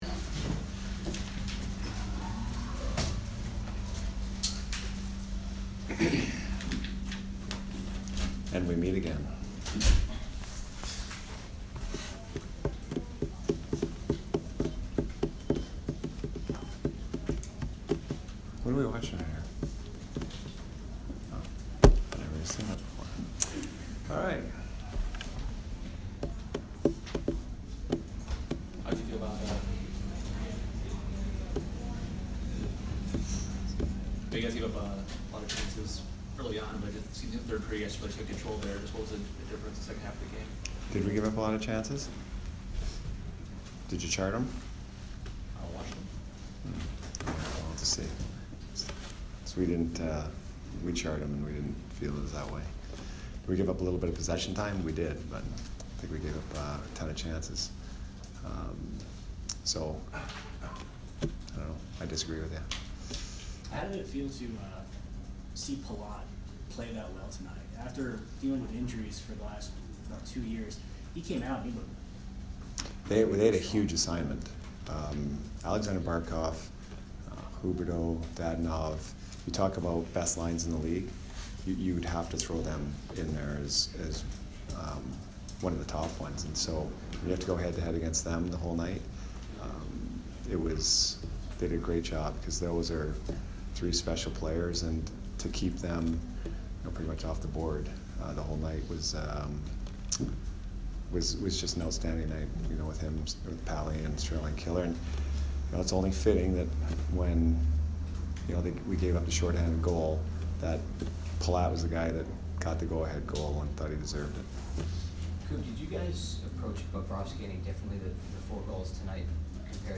Jon Cooper post-game 10/3